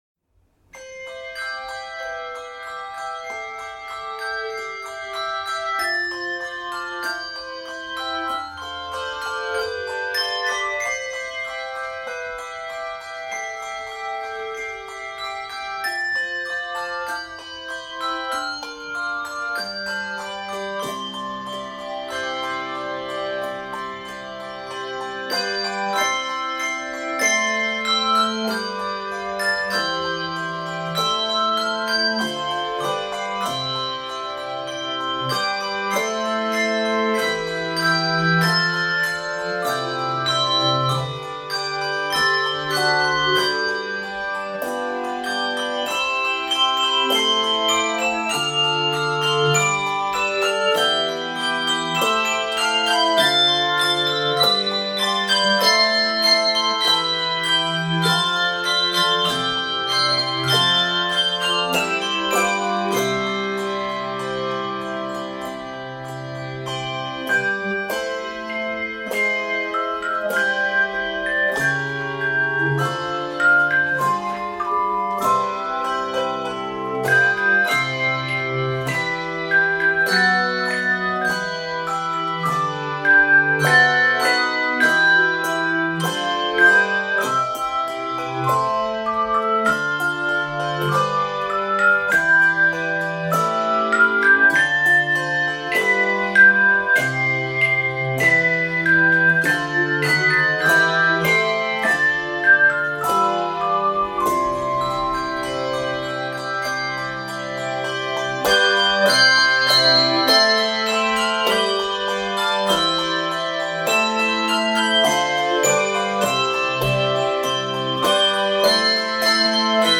captures the innocence and sweetness of the well-loved hymn
Key of C Major.